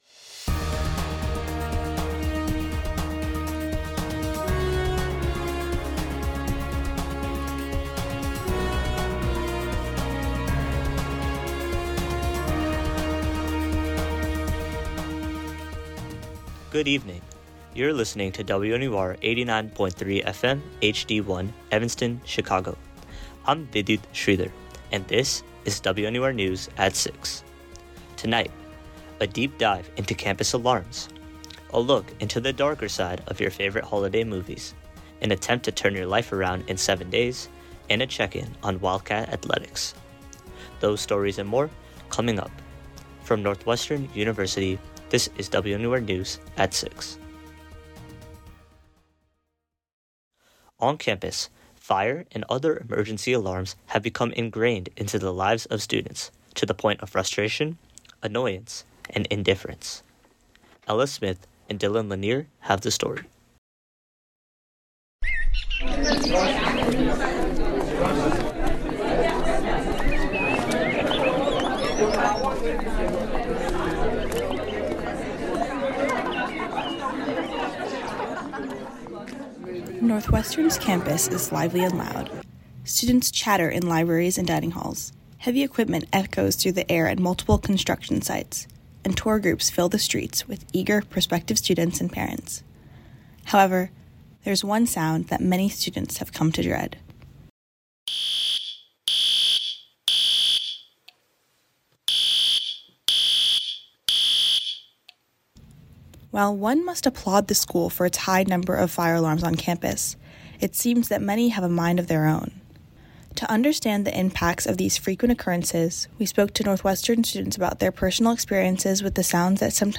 January 14, 2026 A deep dive into campus alarms, a look into the darker side of your favorite holiday movies, an attempt to turn your life around in seven days, and a check-in on Northwestern Athletics. WNUR News broadcasts live at 6 pm CST on Mondays, Wednesdays, and Fridays on WNUR 89.3 FM.